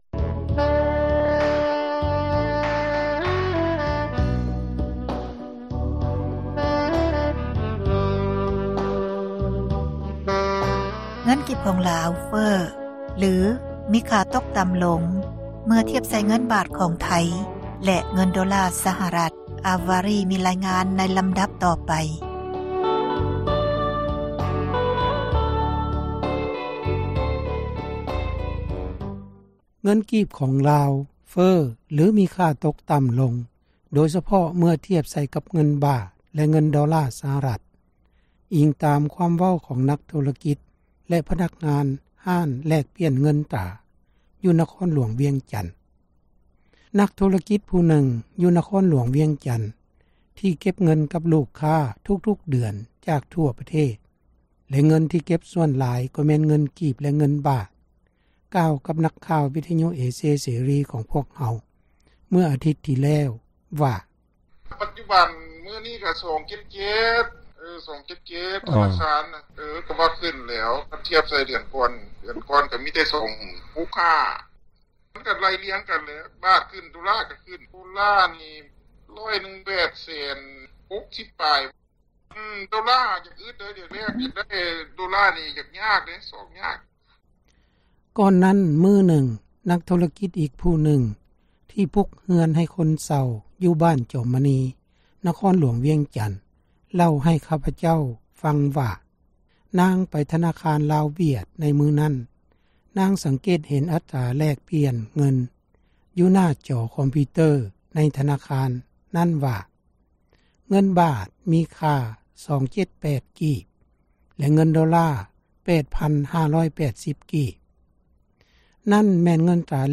ເມື່ອຕົ້ນອາທິດນີ້ ຂ້າພະເຈົ້າ ໄດ້ໂທຣະສັບ ຫາຮ້ານ ແລກປ່ຽນເງິນຕຣາແຫ່ງນຶ່ງ ໃນນະຄອນຫລວງ ວຽງຈັນ ແລະ ຖາມວ່າ: